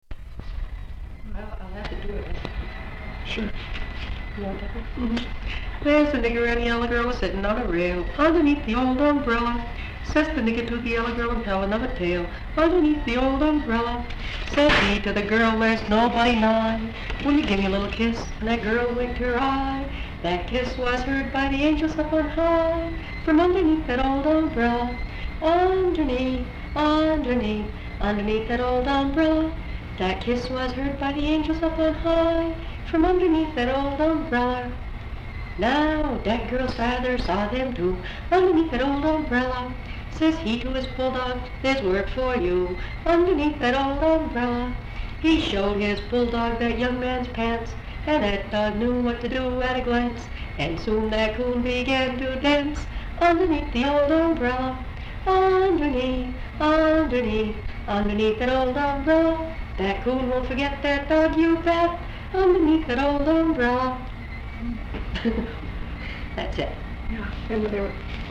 folksongs
Folk songs, English--Vermont
sound tape reel (analog)